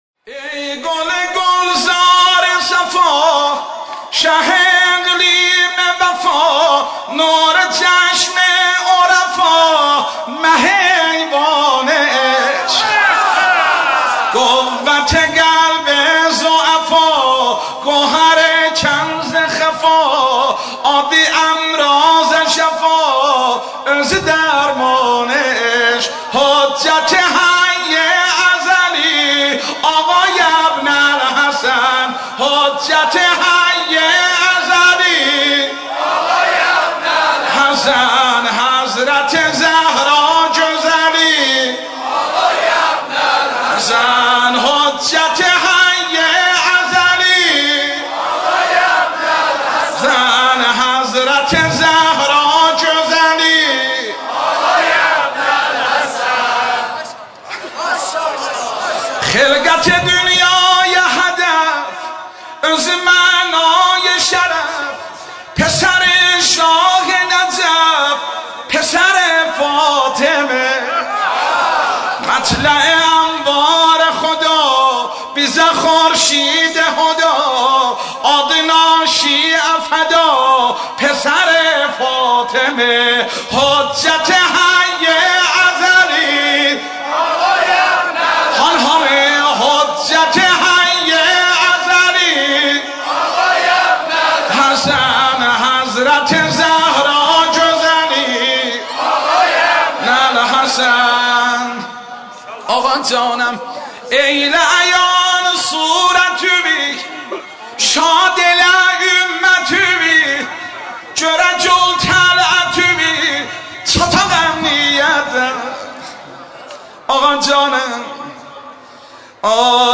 مولودی شاد